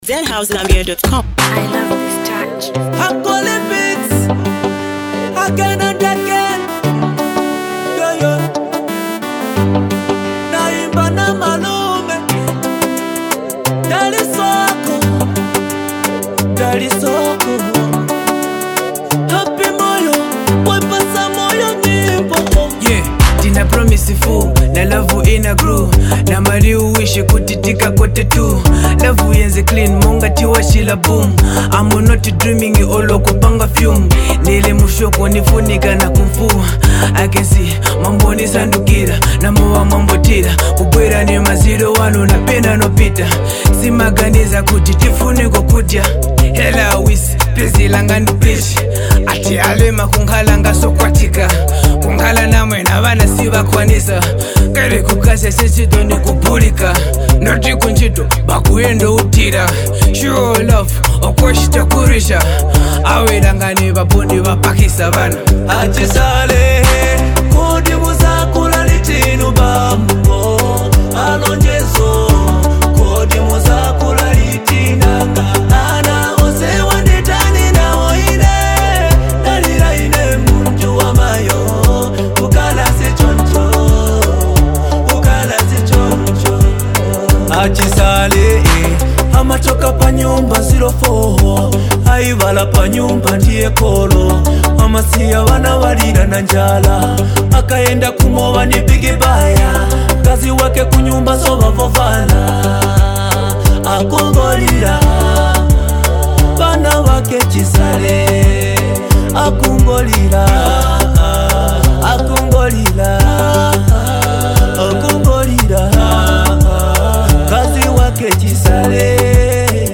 talented rap act
heartfelt track